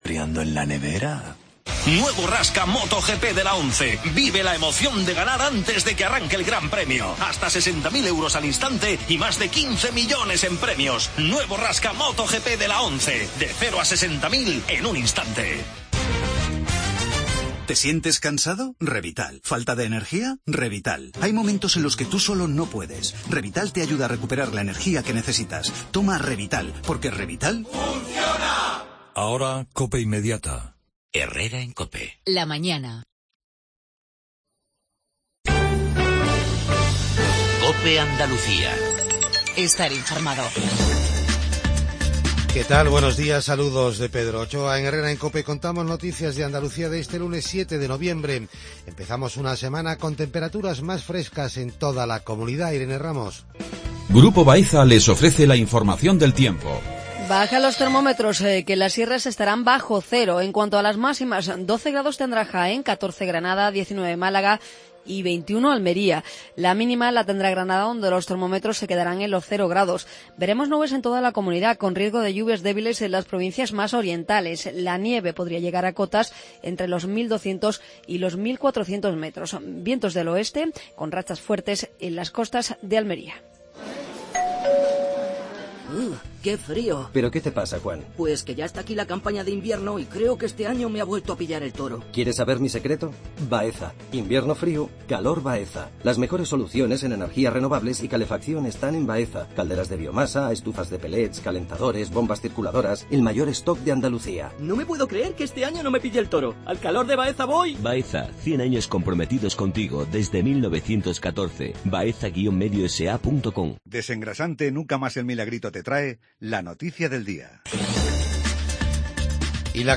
INFORMATIVO REGIONAL/LOCAL MATINAL